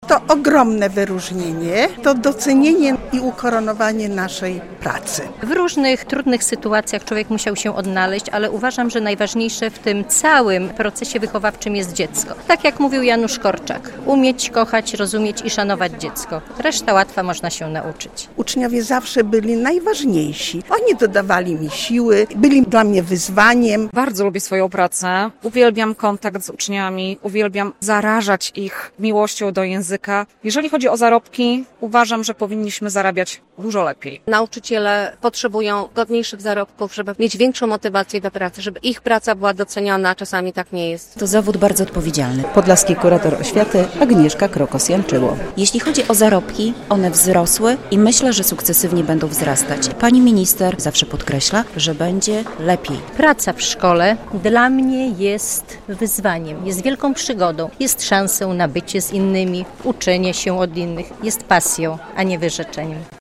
Mówią, że bardzo lubią swoją pracę, że zawód nauczyciela to wciąż dla nich misja, a nie wyrzeczenie. Podczas wojewódzkich obchodów Dnia Edukacji Narodowej - w Sali Koncertowej Zespołu Szkół Muzycznych w Białymstoku - wyróżniający się nauczyciele i pracownicy oświaty otrzymali medale, nagrody i honorowe tytuły.
relacja